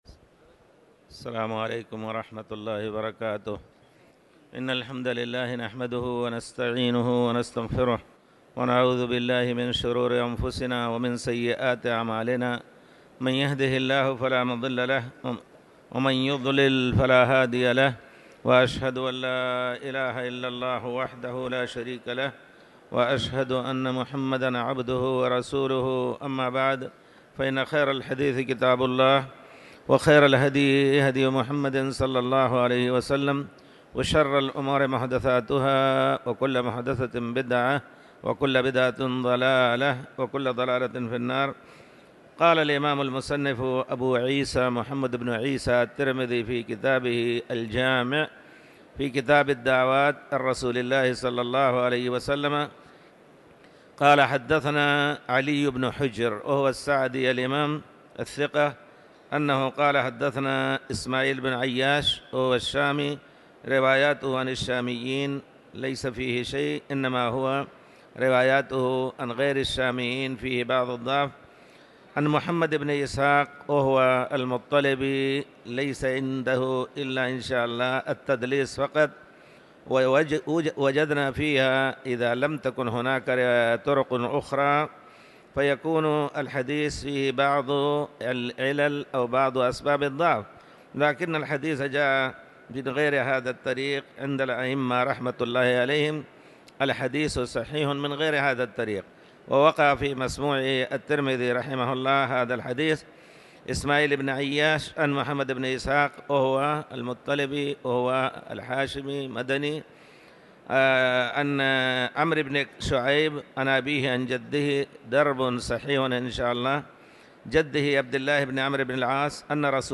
تاريخ النشر ١٥ جمادى الآخرة ١٤٤٠ هـ المكان: المسجد الحرام الشيخ